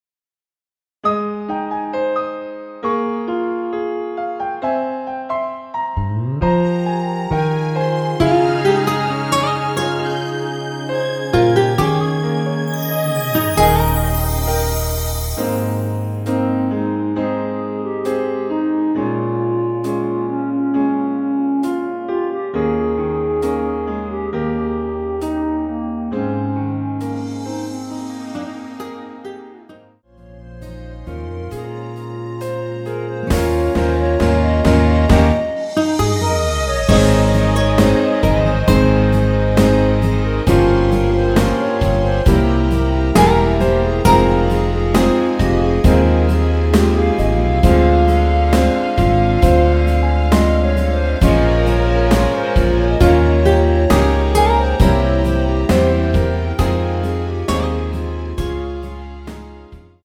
Ab
◈ 곡명 옆 (-1)은 반음 내림, (+1)은 반음 올림 입니다.
앞부분30초, 뒷부분30초씩 편집해서 올려 드리고 있습니다.
중간에 음이 끈어지고 다시 나오는 이유는